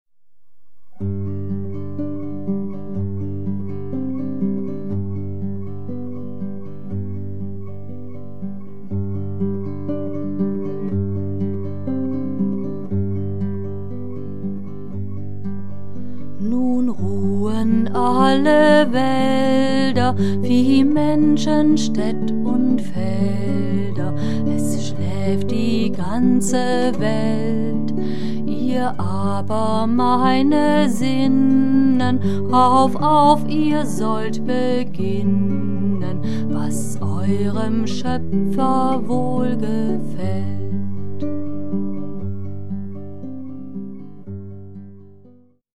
Tonaufnahmen (Multitrack): April-Juni 2006 in Deinstedt
Gesang und Gitarre